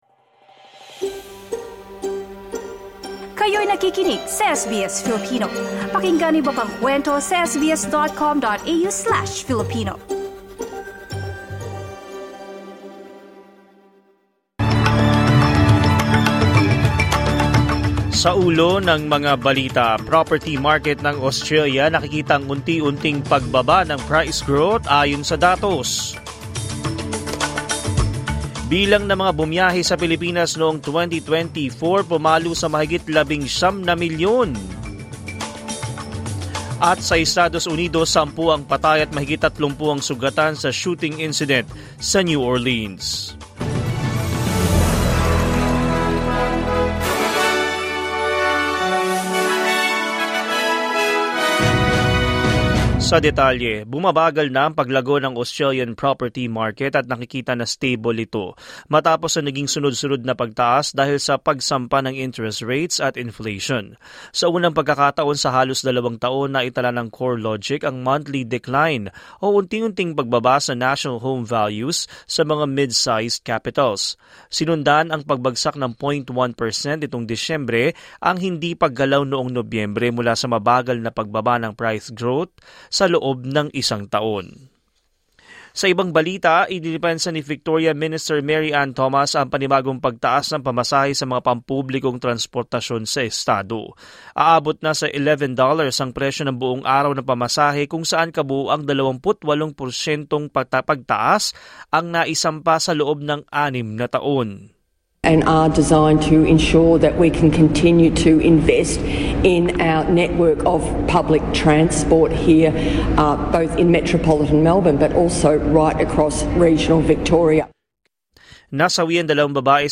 SBS News in Filipino, Thursday 2 January 2025